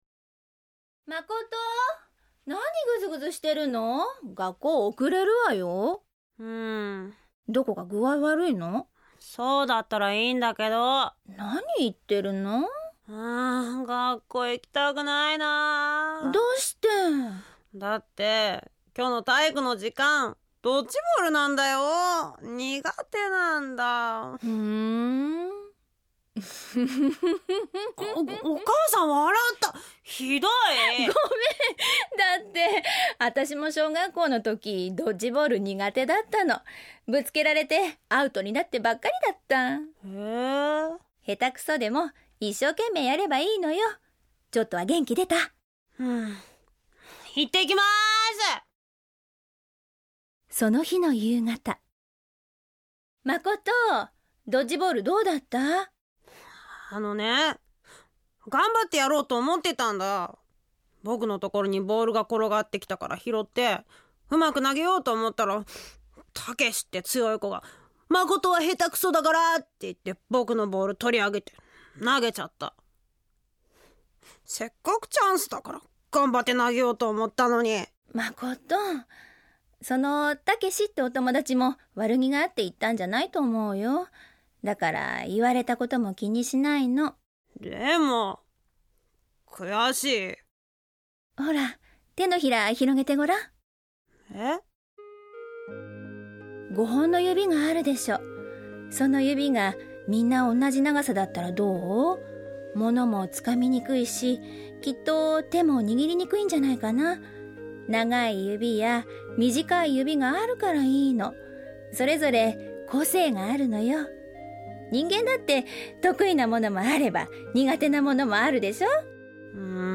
●ラジオドラマ「鈴木家の教訓」